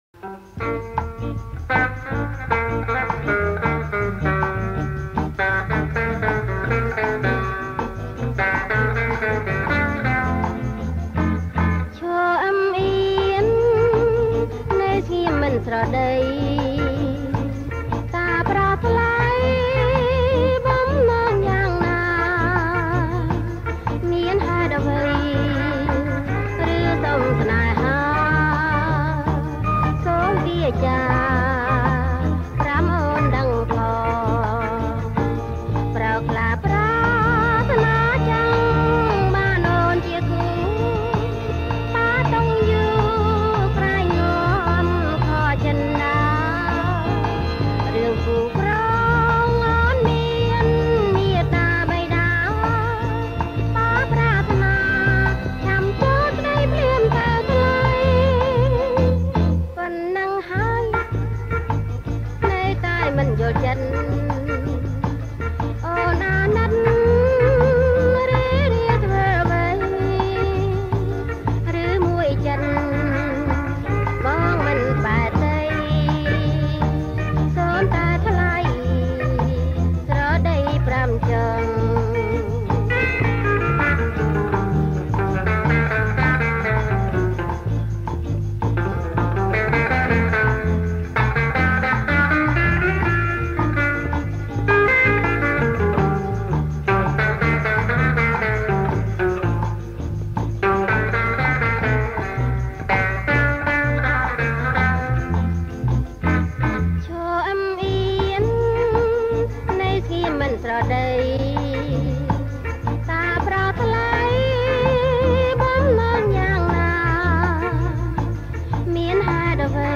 ប្រគំជាចង្វាក់ Bossanova